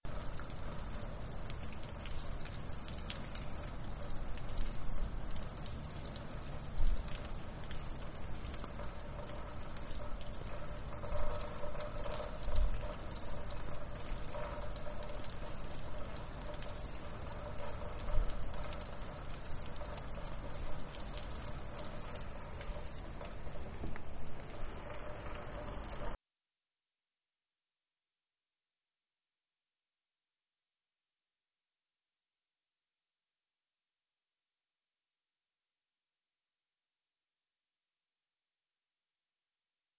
drizzle.wav